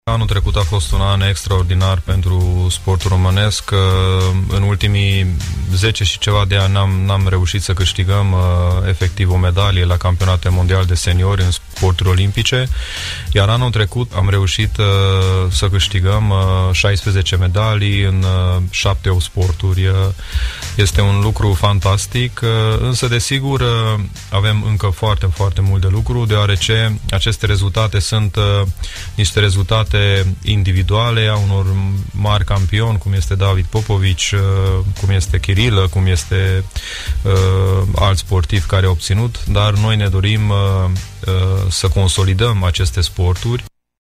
Invitat la emisiunea Pulsul zilei la Radio Tg.Mureș, Eduard Novak a declarat: